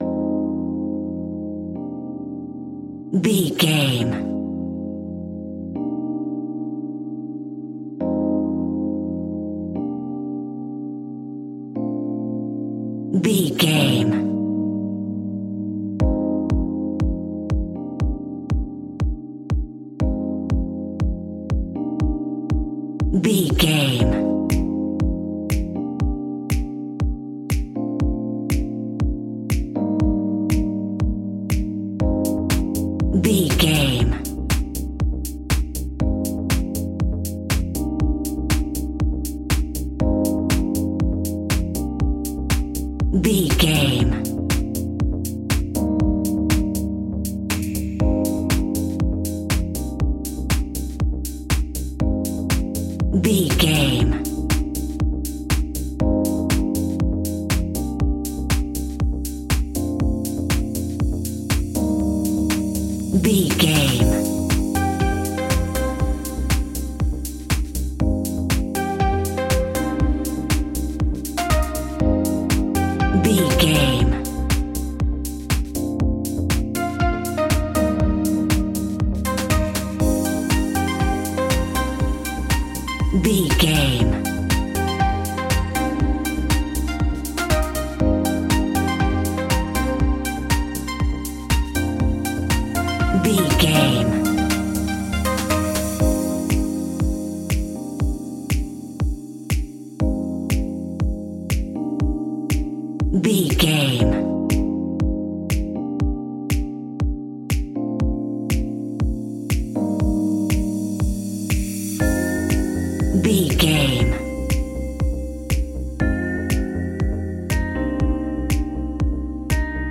Aeolian/Minor
uplifting
driving
energetic
funky
synthesiser
drum machine
electro house
funky house
synth bass